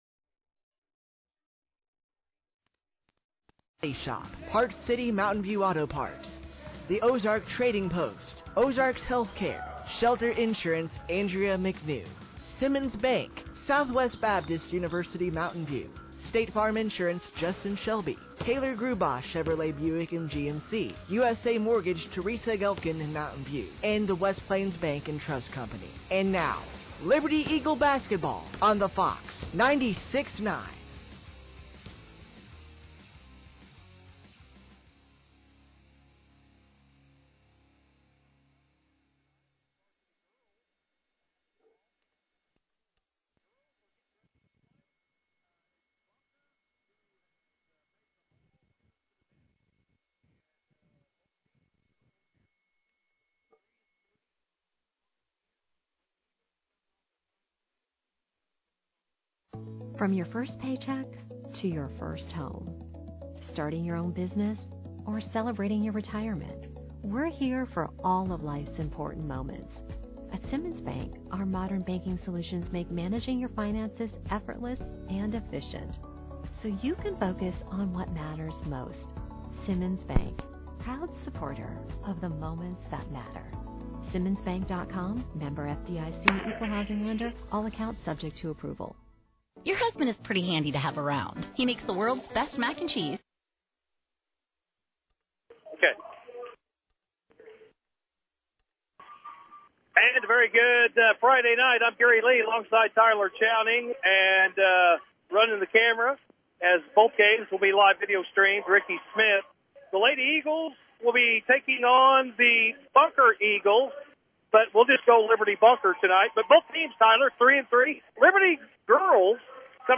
Game Audio Below: The Liberty Lady Eagles faced the Bunker Lady Eagles on Friday night, December 13th, 2024 following the games between both the JV & Varsity Boys teams.